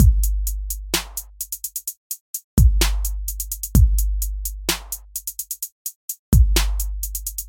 描述：一个简单的陷阱式的欢快的踢腿/刺耳的声音/hihat循环。
Tag: 140 bpm Trap Loops Drum Loops 1.15 MB wav Key : Unknown